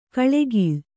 ♪ kaḷegīḷ